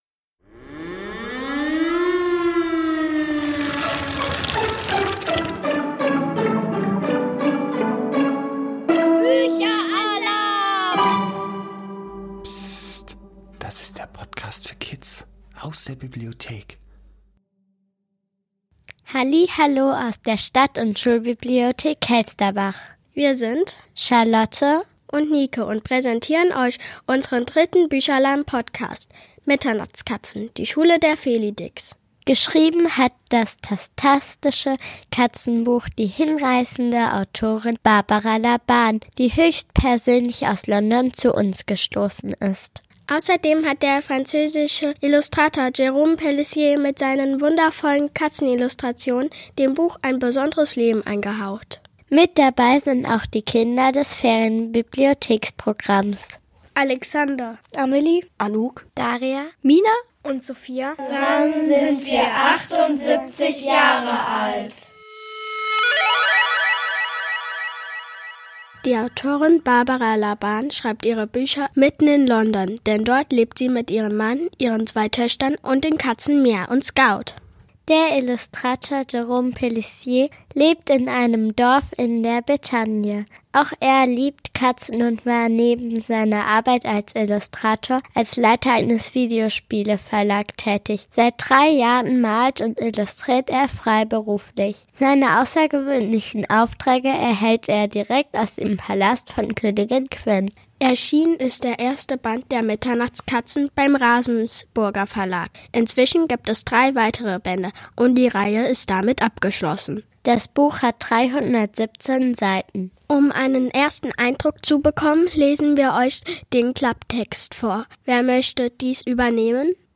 Wir stellen Euch hier den ersten Band „Die Schule der Felidix" vor. Freut euch auf ein tatztastisches Hörerlebnis, das wir im Rahmen der Herbstferien gemacht haben!